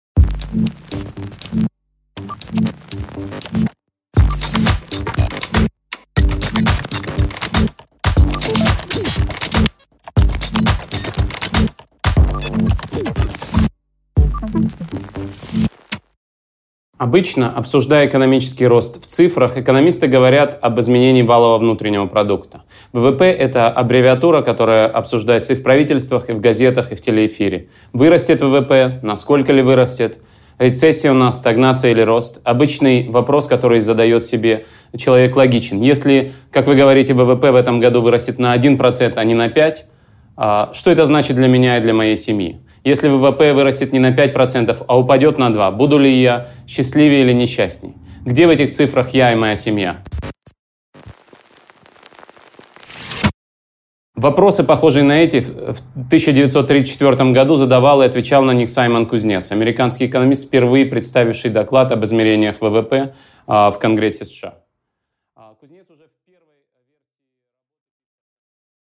Аудиокнига Не в цифрах счастье: экономический рост и другие неважные вещи | Библиотека аудиокниг